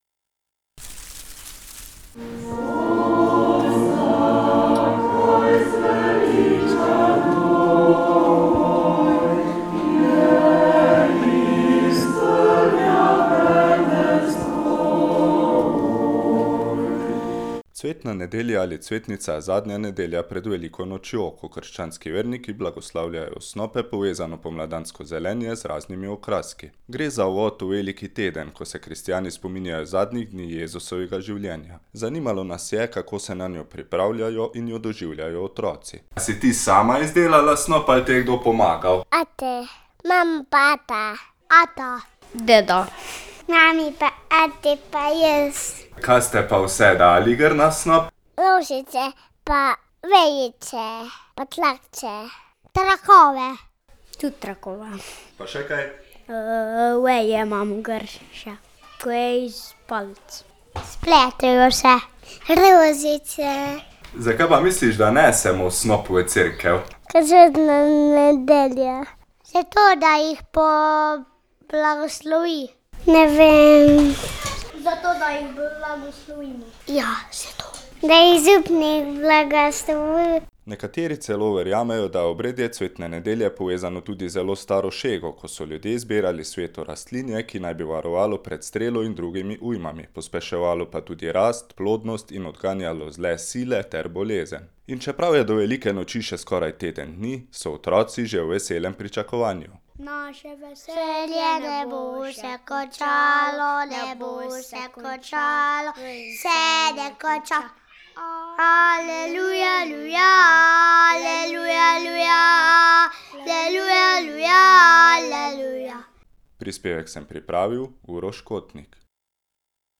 Minula nedelja je bila v znamenju šumenja snopov, prepevanja postnih pesmi in otroške radoživosti: